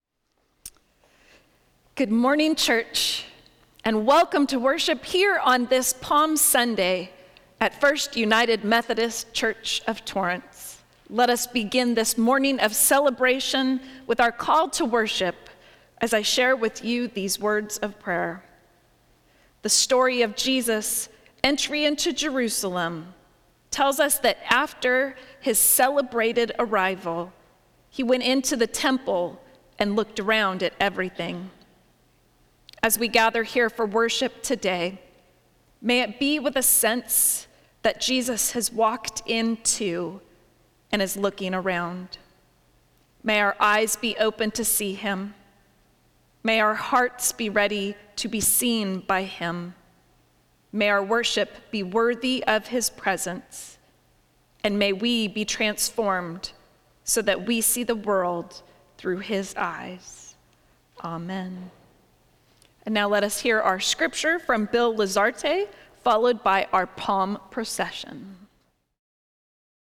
Service of Worship
Welcome and Opening Prayer